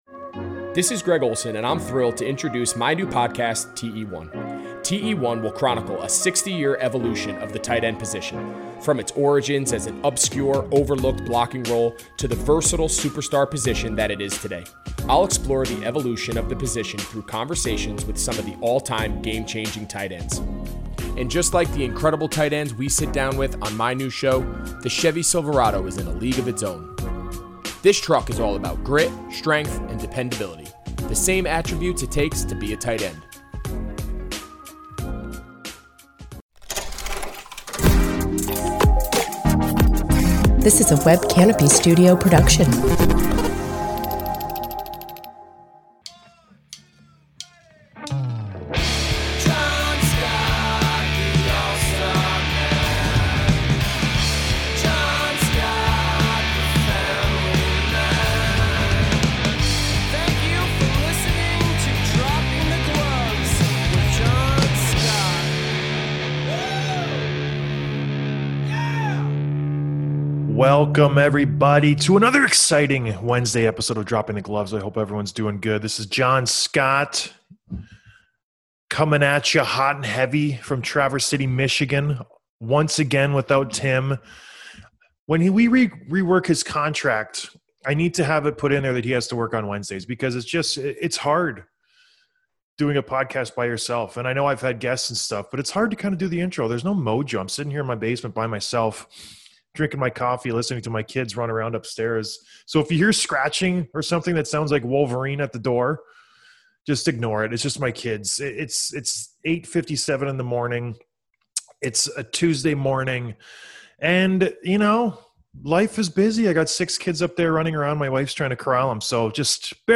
Interview with Al Stalock, MN Wild GoalieAnother former teammate from the Sharks days, Al Stalock joins the show to talk a little bit of his experience in the bubble, what it was like facing Elias Pettersson for a series, and what to make of the revolv...